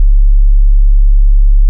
35Hz PUSH CUE TONE.mp3